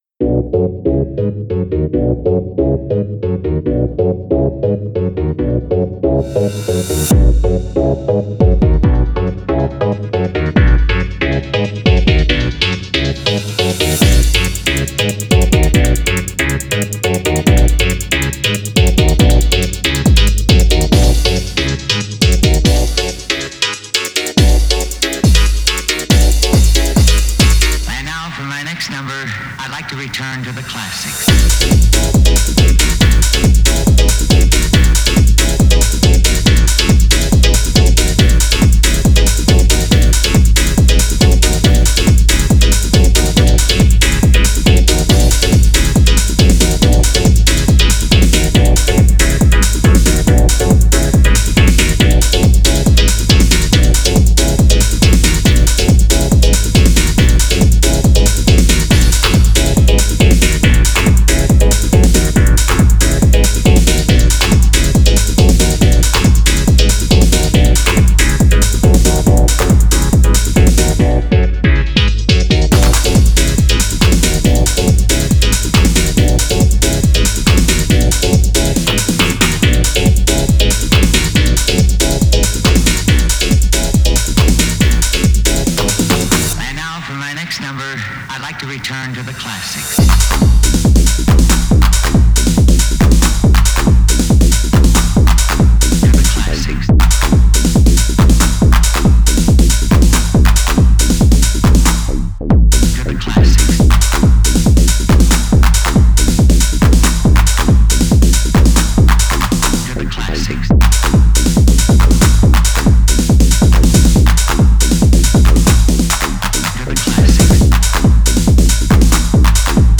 full of edgy chords & hi-hats
congas, arpeggiation & deep pads